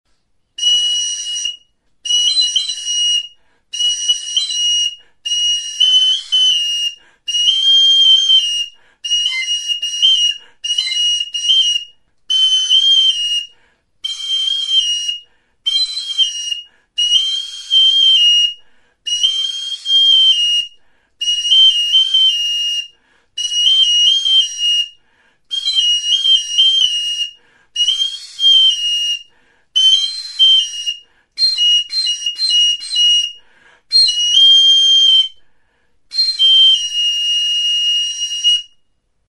Instrumentos de músicaTXILIBITUA
Aerófonos -> Flautas -> Recta (dos manos) + kena
Grabado con este instrumento.
Kanaberazko hiru zuloko bi eskuko moko flauta zuzena da.